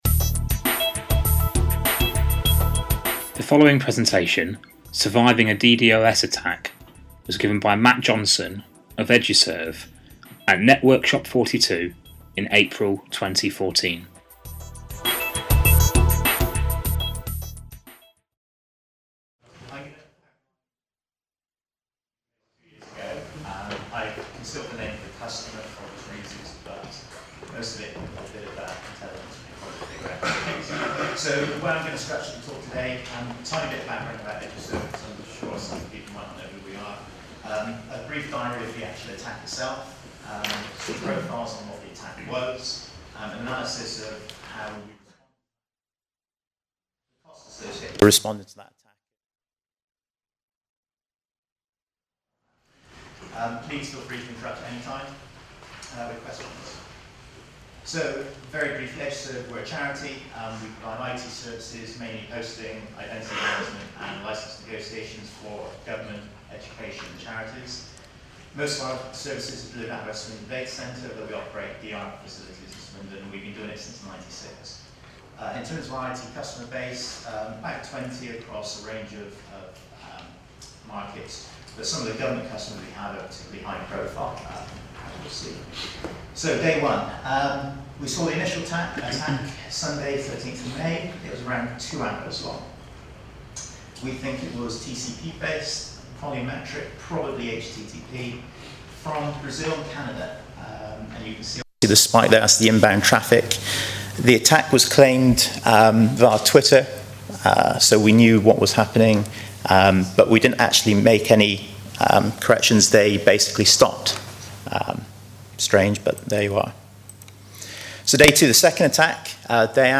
In 2012 a number of government websites, including one hosted by Eduserv came under a concerted DDOS attack from an online activist group. This presentation will provide a real-life insight into the attack methods used, traffic profiles through the period, the range of countermeasures deployed, and the "after-action" report detailing lessons learned